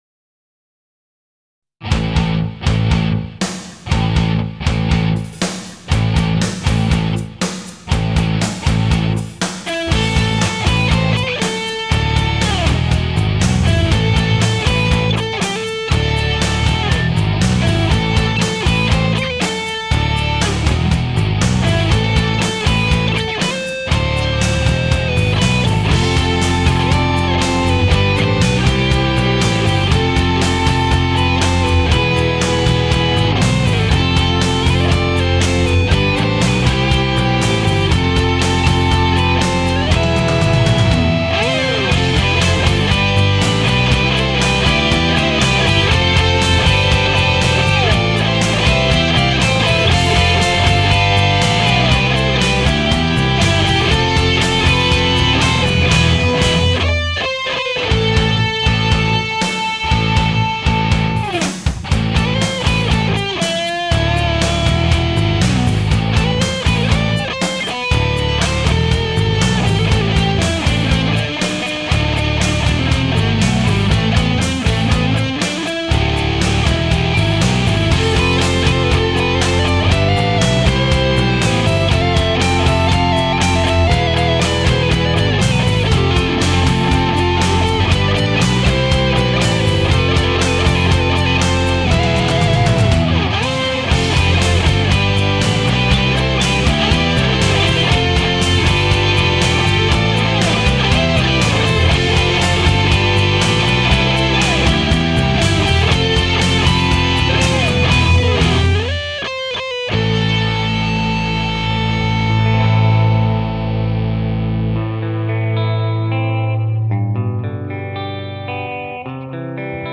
Guitars, Trombones and Sequencing
Recorded 2008 in New York City